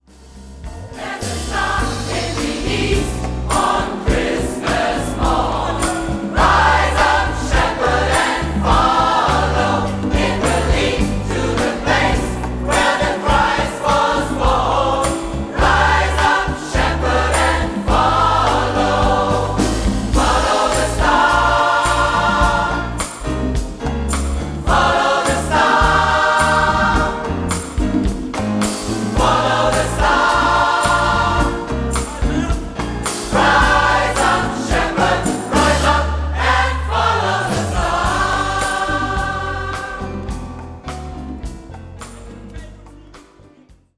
• SAB/SSA, Solo + Piano